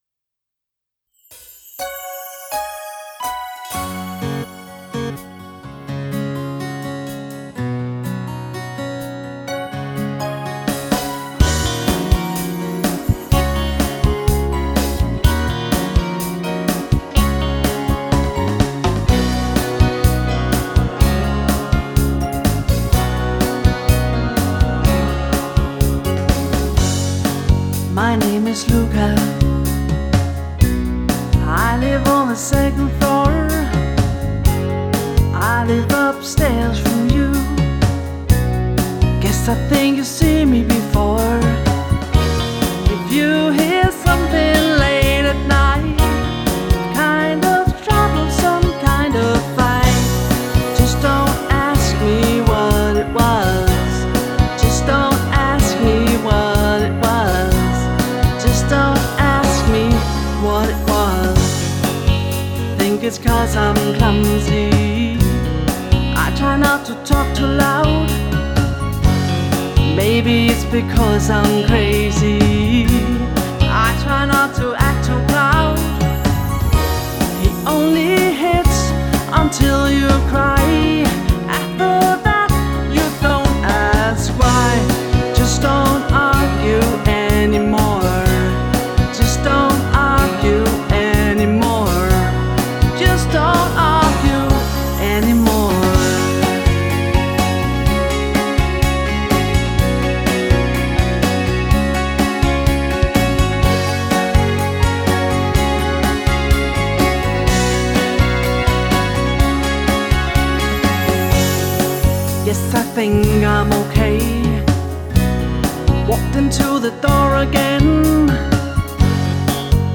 • Coverband
• Duo eller trio